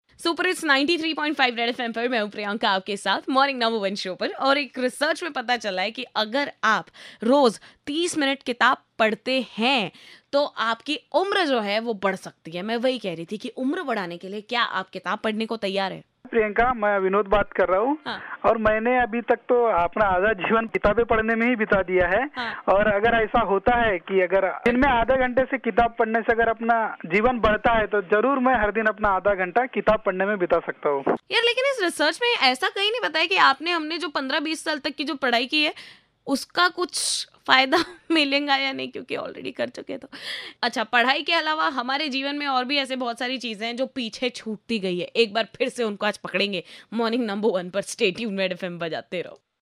callers interaction